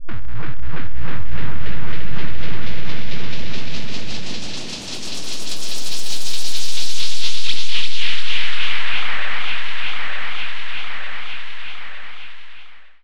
10 Rhodes FX 010.wav